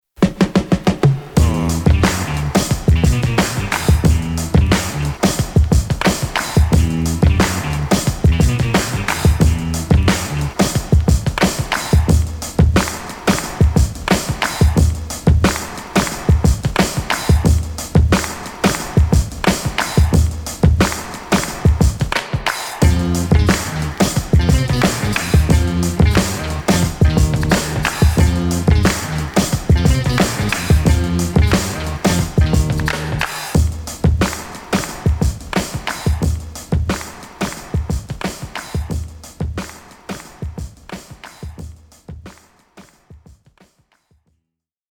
ジャンル(スタイル) JAPANESE HIP HOP